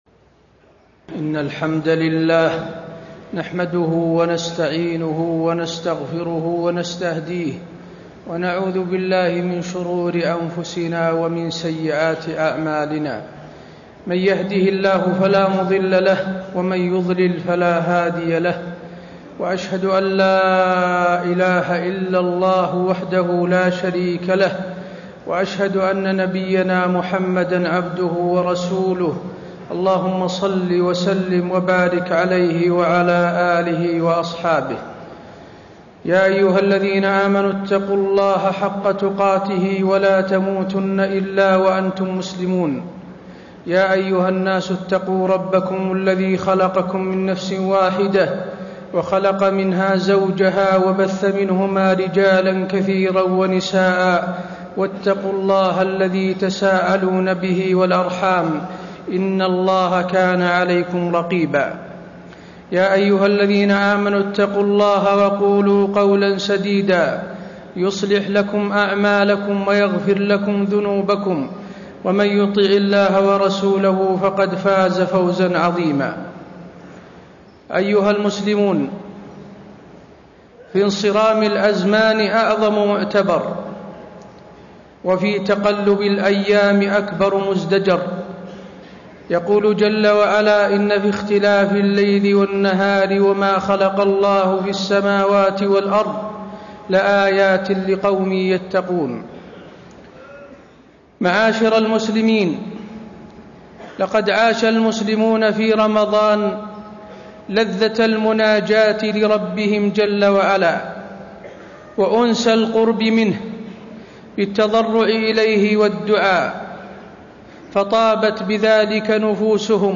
تاريخ النشر ٦ شوال ١٤٣٣ هـ المكان: المسجد النبوي الشيخ: فضيلة الشيخ د. حسين بن عبدالعزيز آل الشيخ فضيلة الشيخ د. حسين بن عبدالعزيز آل الشيخ ماذا بعد رمضان